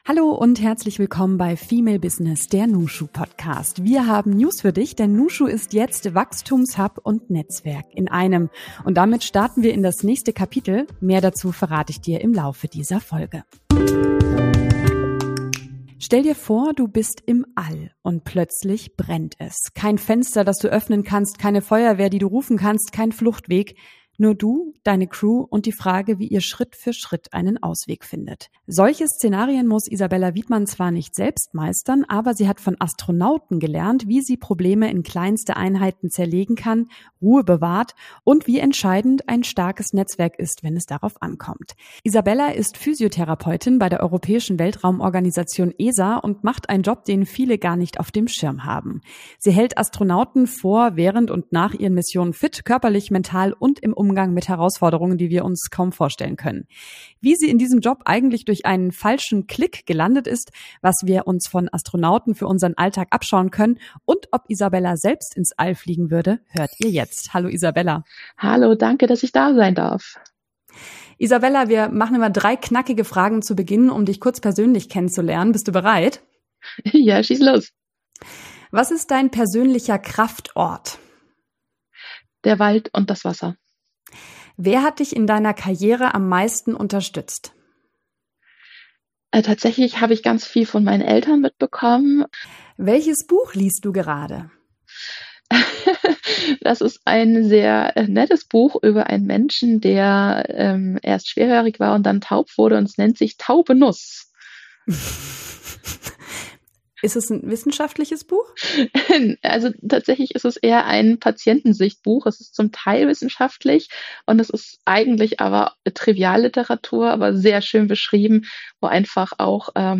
Ein Gespräch über Zufälle, die Leben verändern, über die Kraft von Neugier – und darüber, warum wir alle ein bisschen wie Astronaut:innen denken sollten. Außerdem verraten wir dir, was neu bei nushu ist: Mit unseren Learning Journeys zu Strategischem Netzwerken und Personal Branding wächst du über dich hinaus.